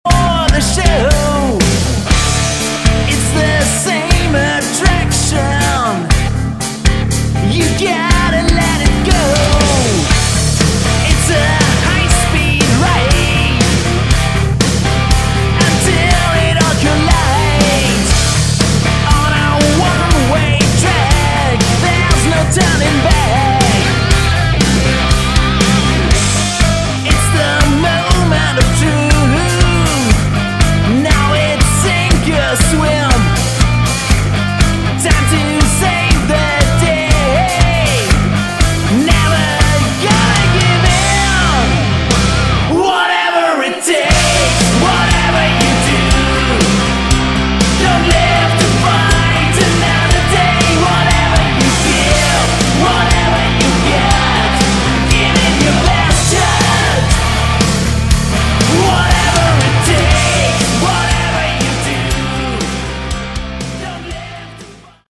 Category: Hard Rock
vocals, guitar, bass and keyboards
drums